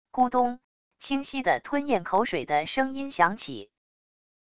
咕咚……清晰的吞咽口水的声音响起wav音频